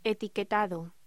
Locución: Etiquetado